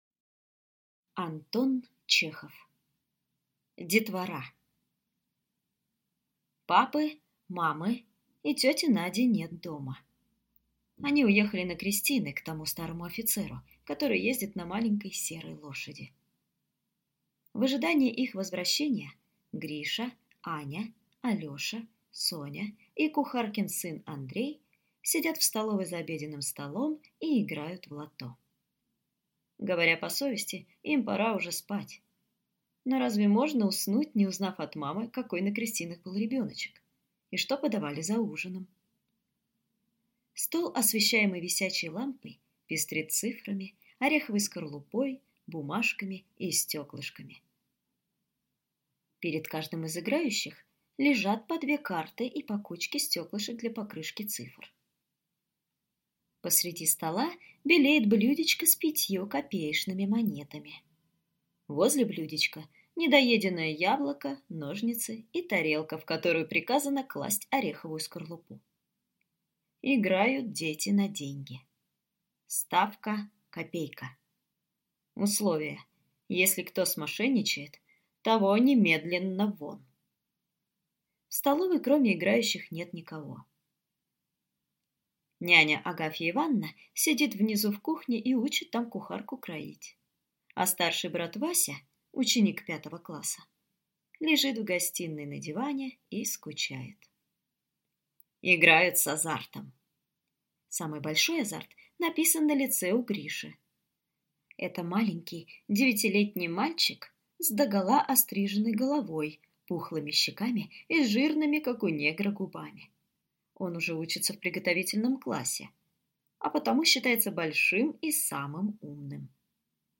Aудиокнига Детвора